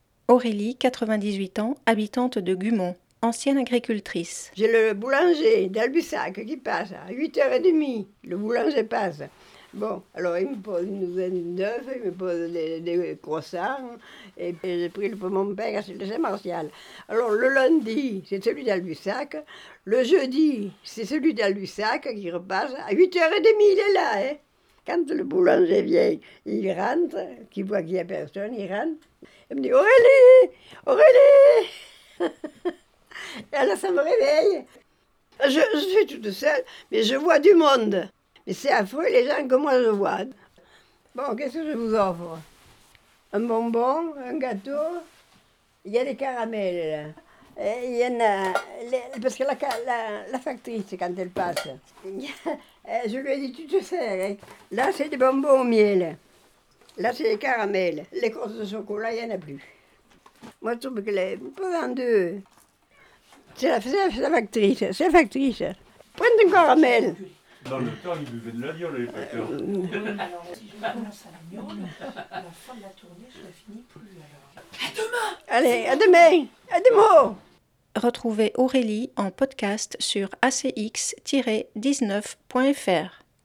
Mois de la Femme interview